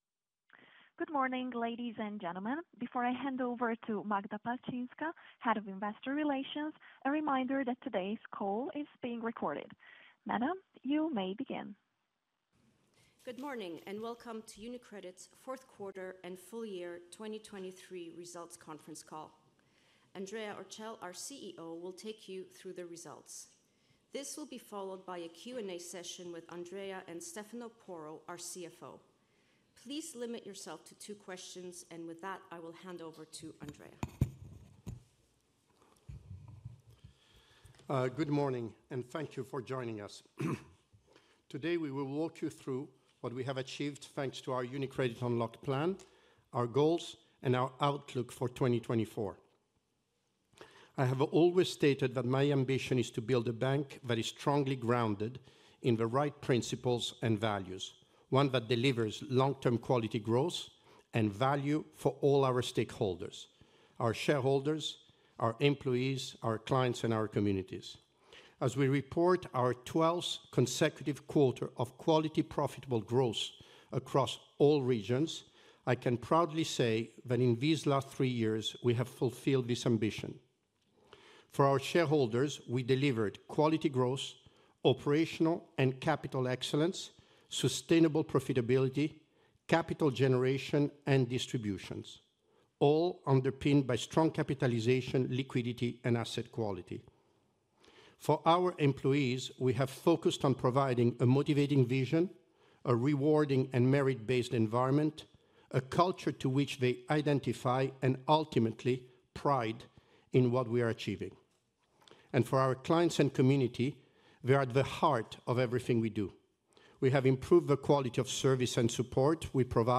4Q23 Conference call audio recording